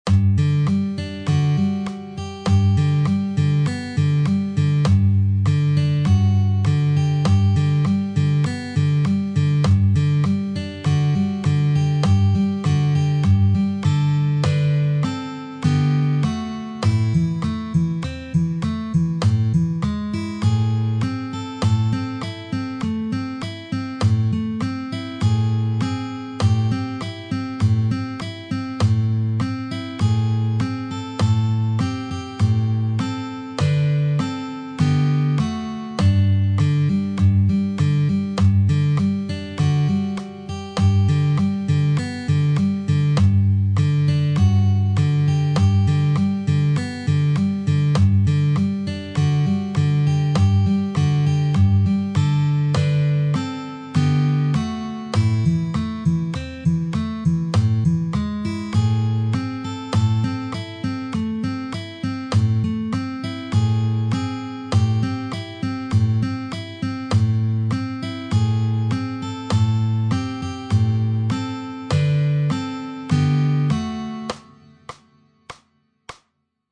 Arrangiamento molto bello è semplice per chitarra sola!